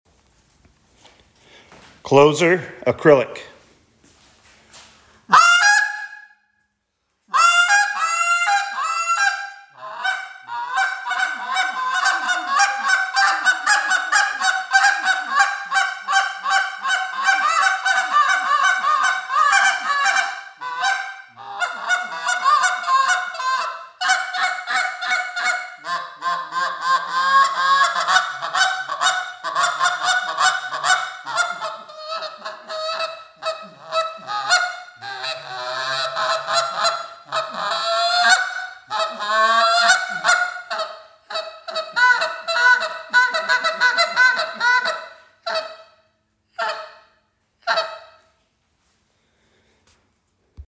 Canada Goose Call
• Maximum Volume & Versatility: This call has exceptional range of sound and power to reach distant flocks.
• Effective on All Geese: Designed to produce the realistic, versatile vocalizations.
• Guts of Glory System: Features our fourth-generation, broken-in gut system, ensuring top-tier, realistic tone right out of the box.